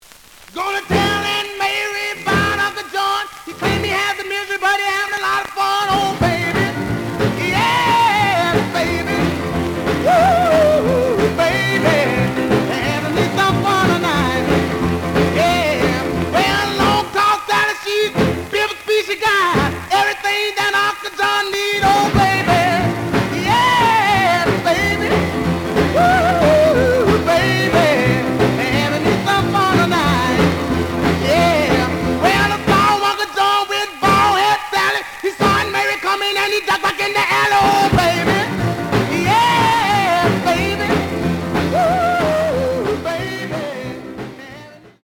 試聴は実際のレコードから録音しています。
●Genre: Rhythm And Blues / Rock 'n' Roll
G+, G → 非常に悪い。ノイズが多い。